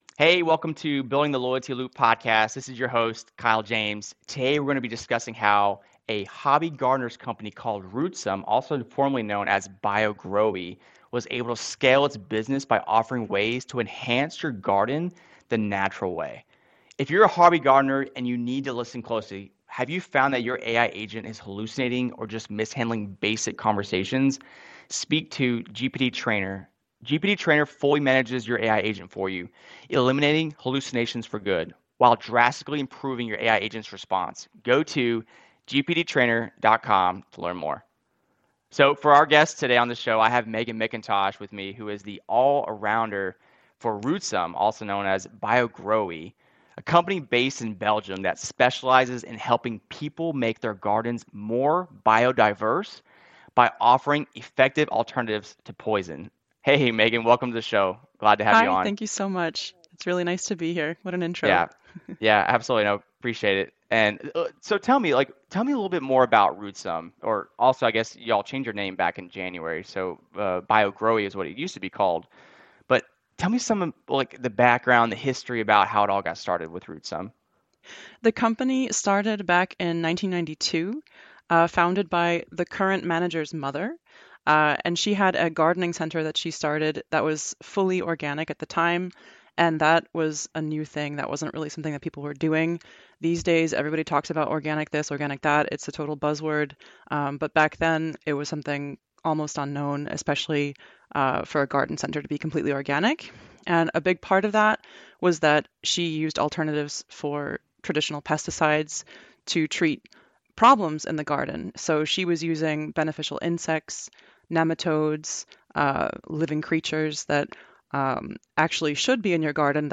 The conversation also touches on the impact of pesticides, the significance of educating customers, and how AI is being utilized to streamline business processes.